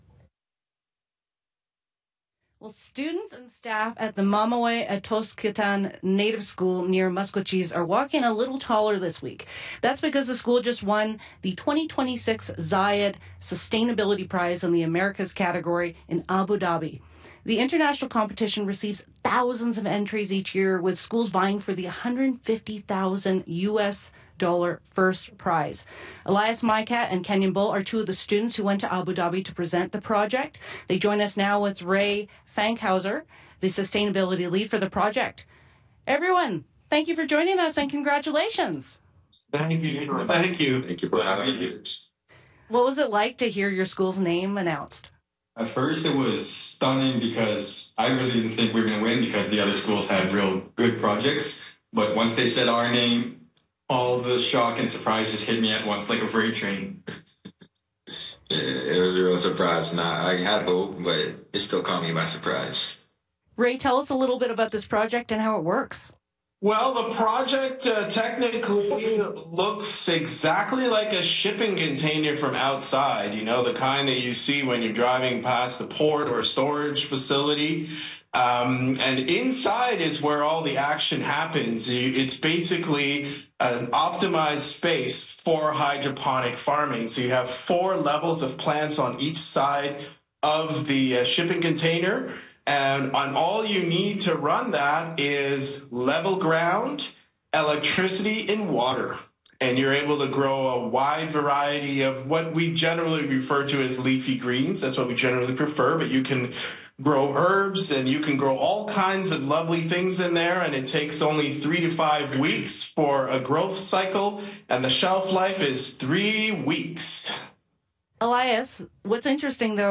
CBC Radio interview Zayed Prize Winners
A rare 6-minute interview during prime time, aired Jan 15, 2026